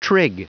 Prononciation du mot trig en anglais (fichier audio)
Prononciation du mot : trig